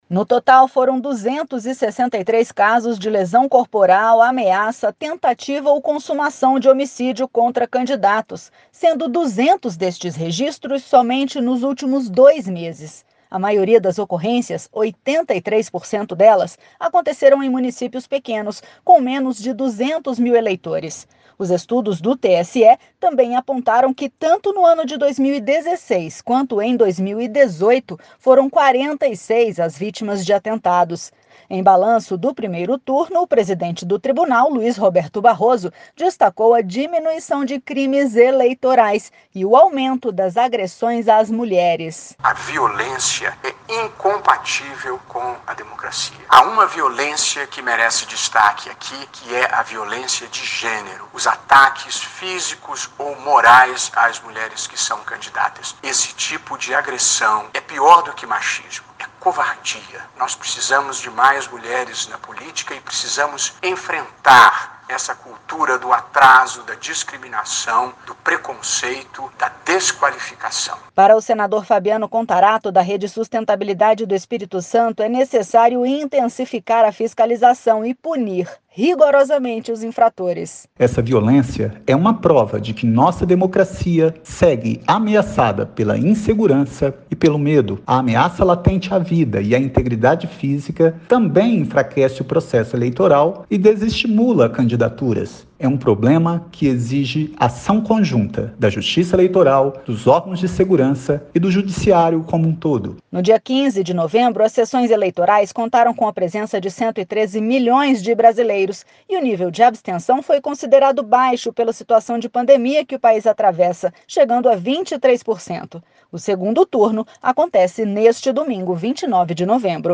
Roberto Barroso
Senador Fabiano Contarato